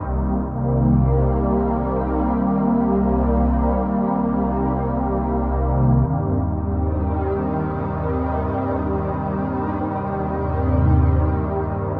Ambient / Sphere / SYNTHPAD019_AMBNT_160_C_SC3(R).wav